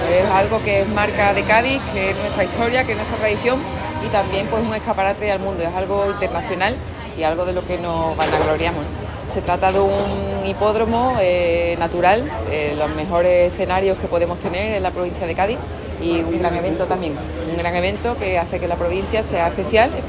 Presidenta-carreras-de-caballos-mp3.mp3